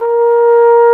Index of /90_sSampleCDs/Roland LCDP12 Solo Brass/BRS_Cornet/BRS_Cornet 2